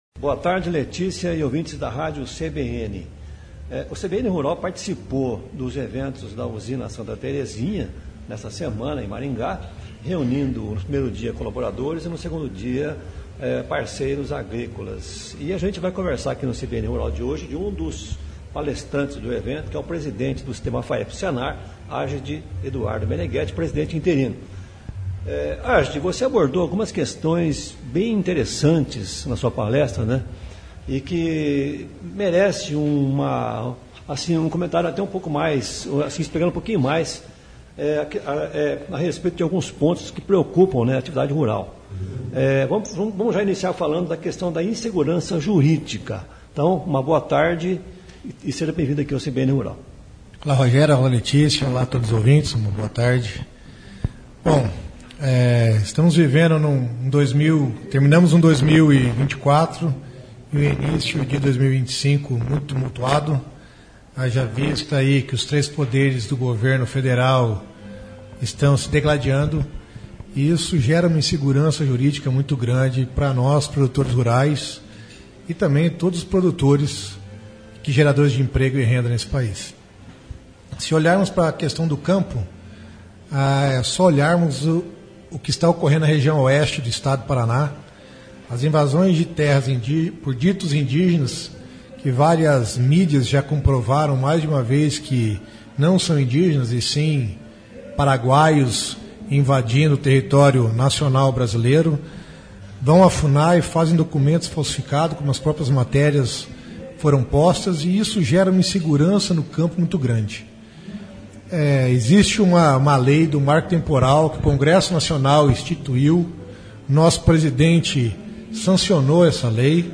Ele concedeu entrevista ao CBN Rural sobre esse assunto.